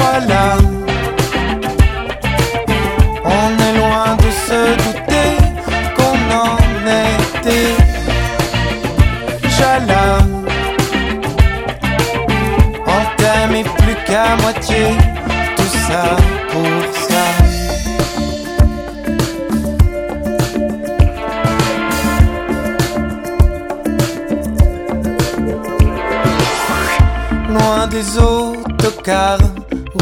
"enPreferredTerm" => "Chanson francophone"